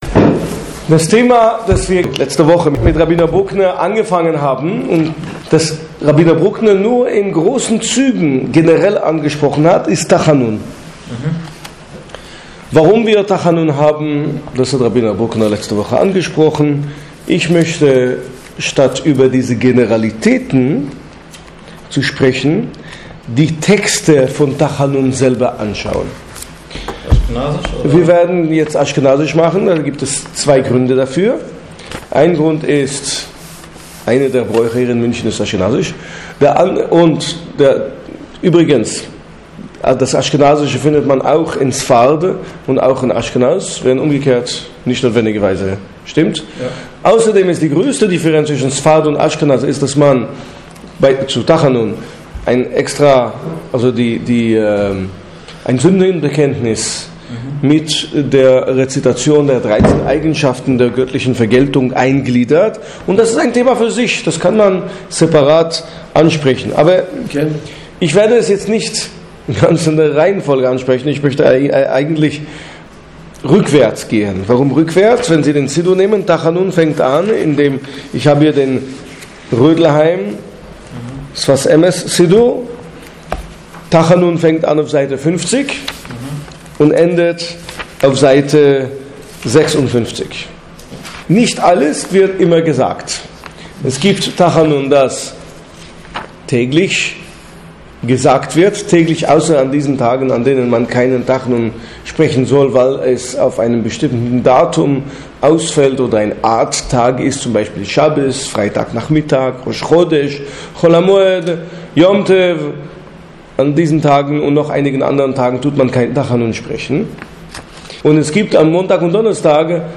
Tachanun, die Texte, die unmittelbar nach der Amidá (dem Stehgebet) des Morgen- und Nachmittagsgebets gesprochen werden, gehören zu den berührendsten Texten des täglichen Gebetes. Dessen Hauptbestandteile, Psalm 6 und das liturgische Gedicht Schomer Jissra'él werden in diesem Vortrag erlätert.